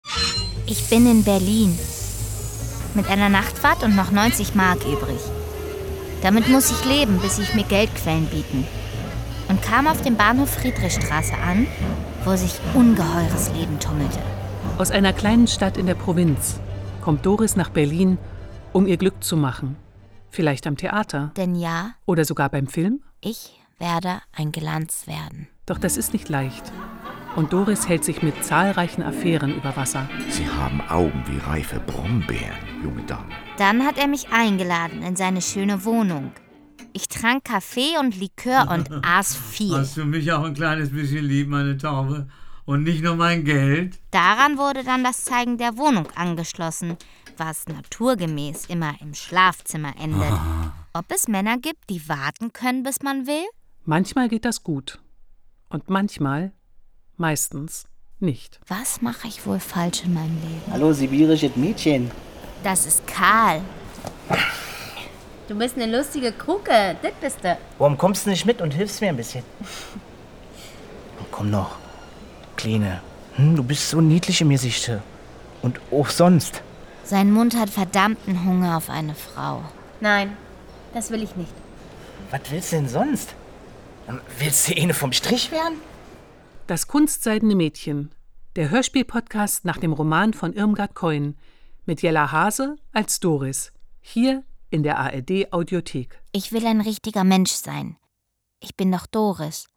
Bücher Kunst Audio Drama Geschichtenerzählen Rundfunk Berlin-brandenburg Tabula Rasa